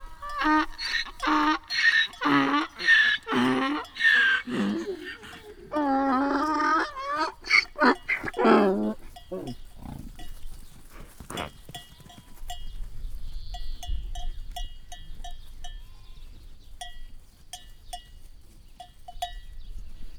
Directory Listing of /_MP3/allathangok/termeszetben/magyarparlagiszamar_professzionalis2014/
balrolesjobbrolis_laszitanya_sds00.20.WAV